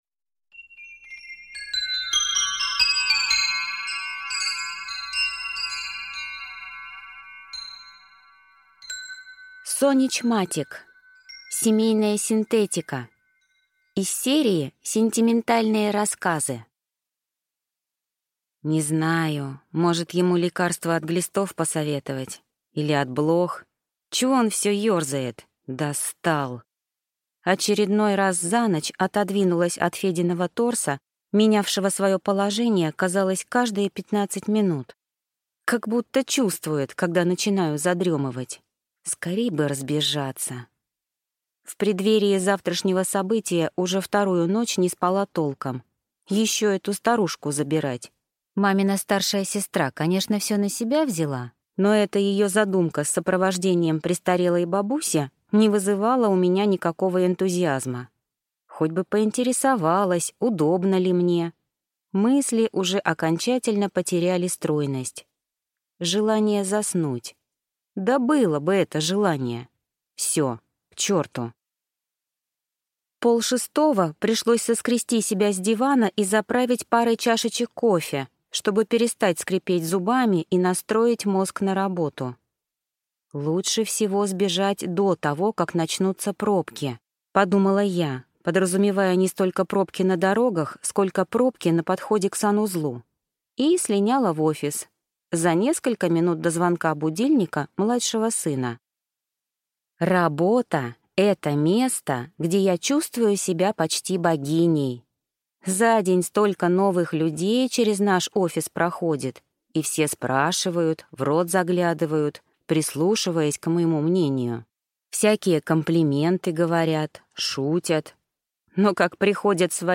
Аудиокнига Семейная синтетика | Библиотека аудиокниг
Прослушать и бесплатно скачать фрагмент аудиокниги